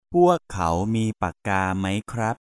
M